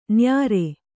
♪ nyāre